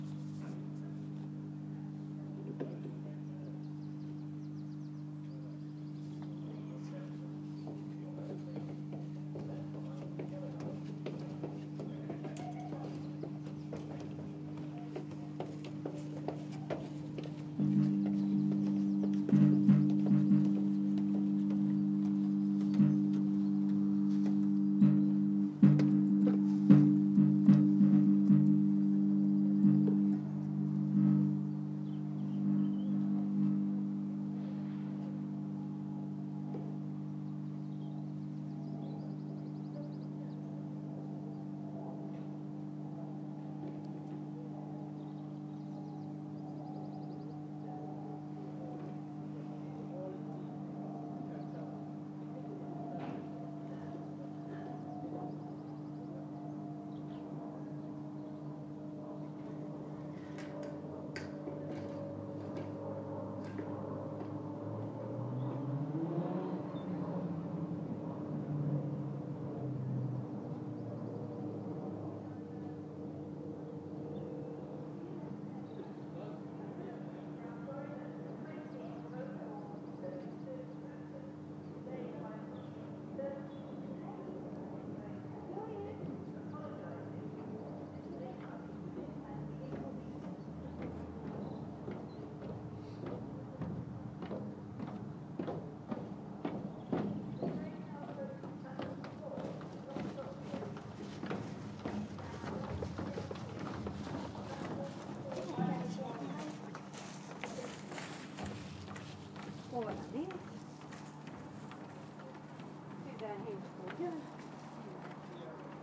Faulty lamp humming at Elstree & Borehamwood station